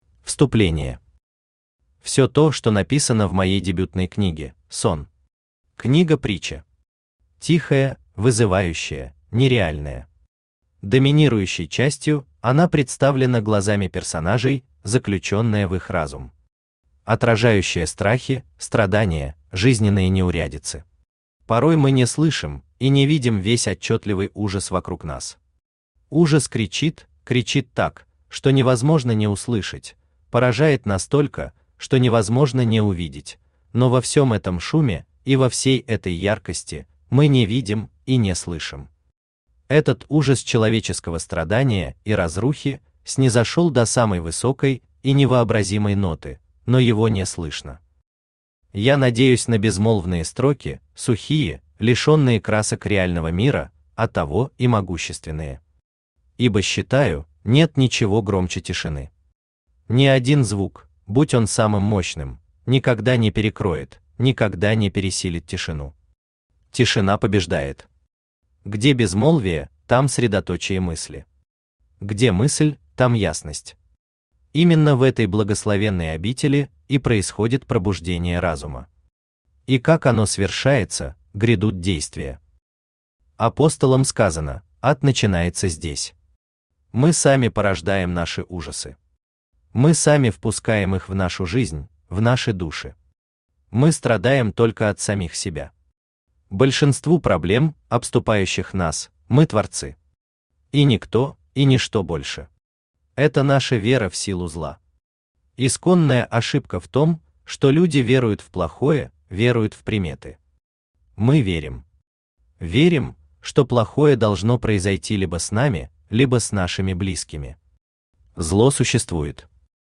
Аудиокнига И.Н.Ф.Е.Р.Н.О. Ад начинается на Земле | Библиотека аудиокниг
Aудиокнига И.Н.Ф.Е.Р.Н.О. Ад начинается на Земле Автор КИРИЛЛ МИХАЙЛОВИЧ ДЕНИСЕНКО Читает аудиокнигу Авточтец ЛитРес.